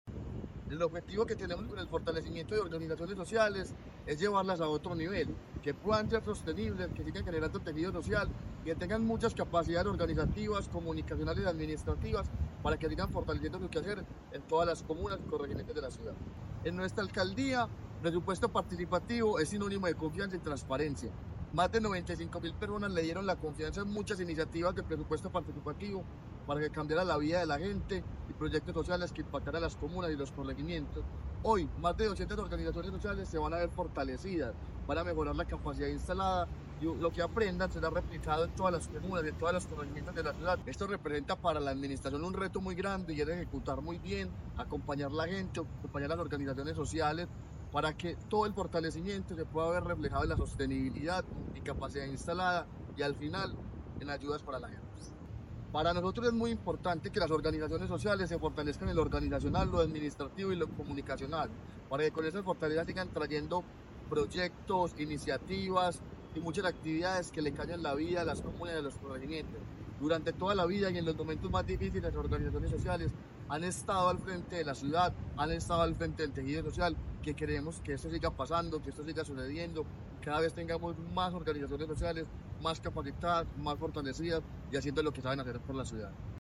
Declaraciones-secretario-de-Participacion-Ciudadana-Camilo-Cano-Montoya-3.mp3